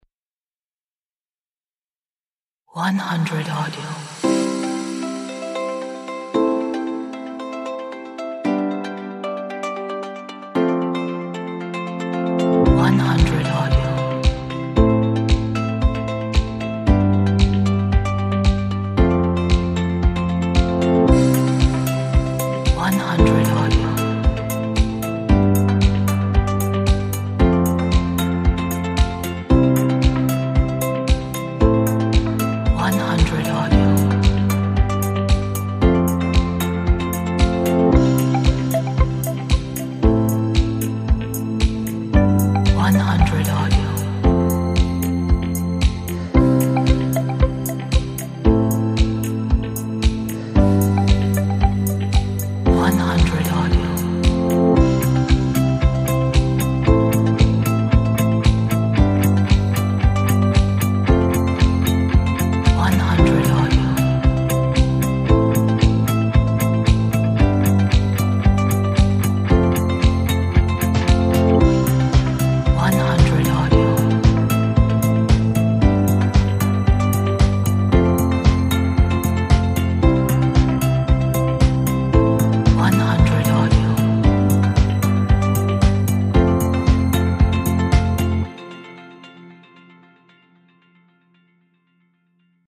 Medical, positive, optimistic track!